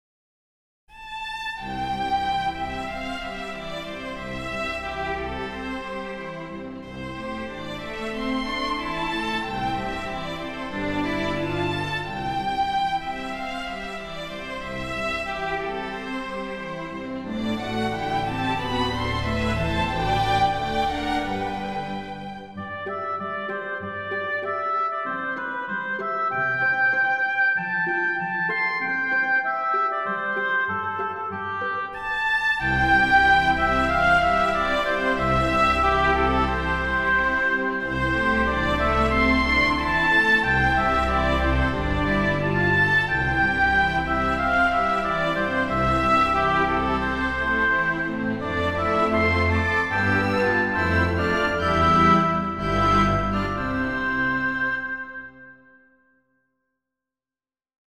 結婚式向き音楽特集For Wedding
トランペット、オルガン